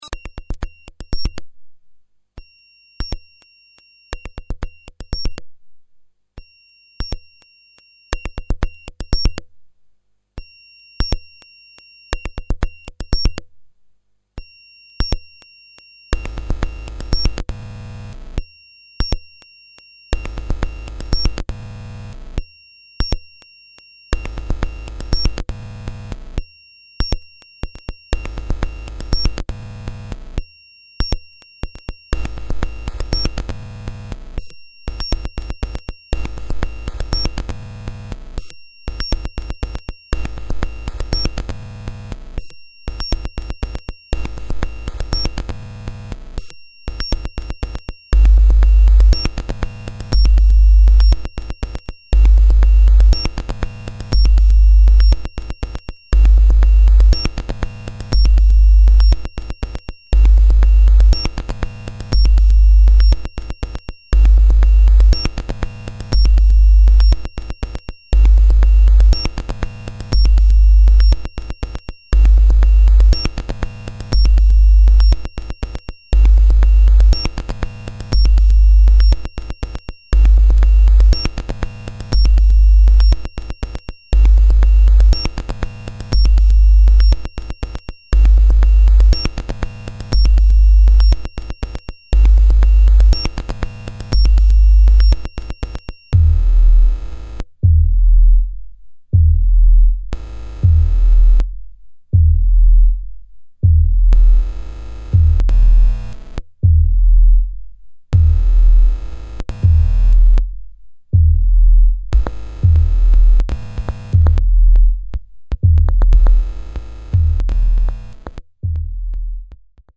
seminal minimalist project